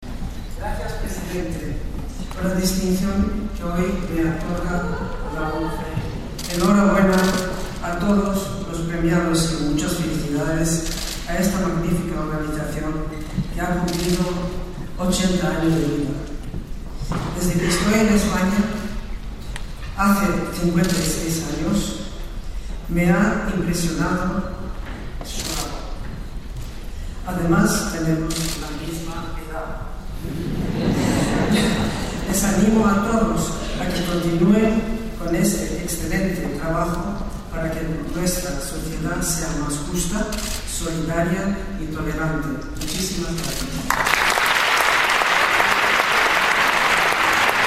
más justa, solidaria y tolerante formato MP3 audio(0,91 MB), manifestó SM la reina doña Sofía, al recoger de manos de Miguel Carballeda su Premio Solidario ONCE Comunidad de Madrid 2018, en la categoría de Persona Física.